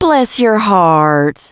piper_kill_01.wav